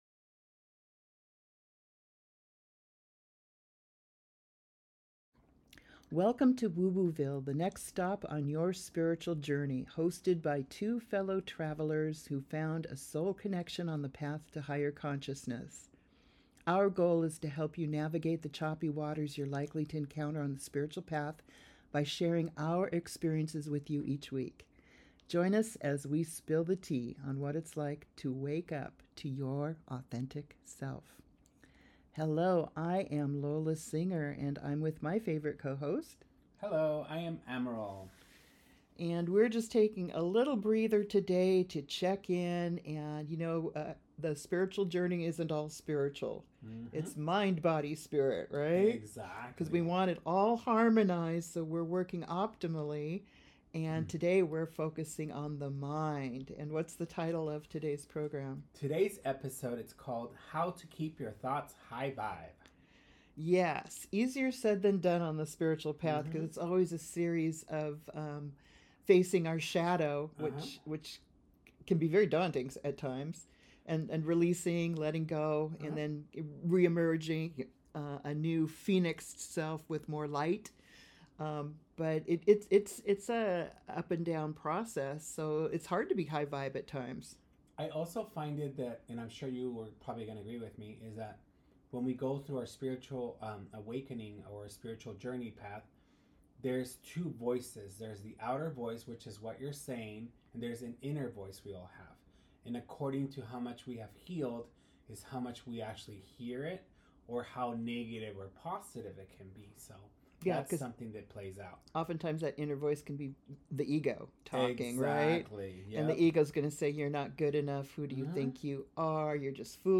A comforting, uplifting conversation for anyone learning to stay high-vibe even on the messy days.